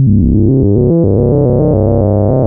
JUP 8 C2 11.wav